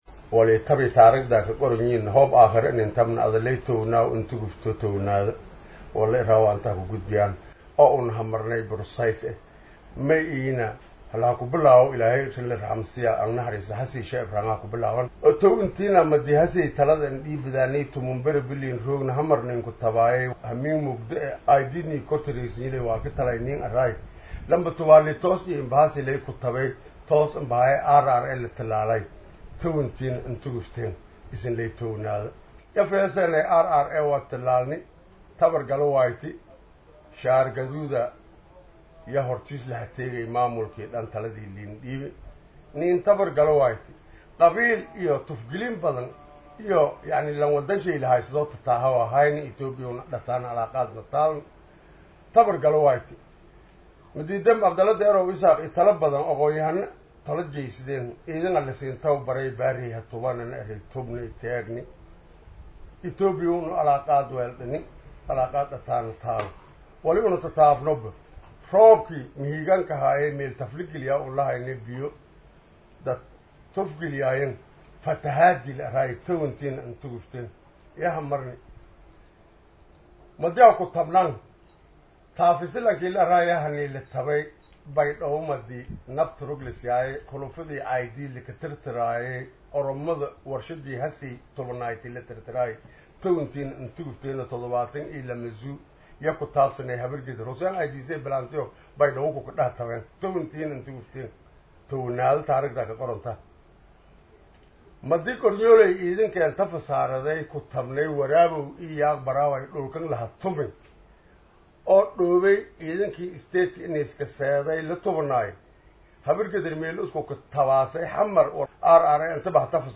Dhageyso wareysi xasaasi ah ibdow Aaase Waa Mudane Ka Tirsan BKGS Eedeymaha Loo soo Jeediyey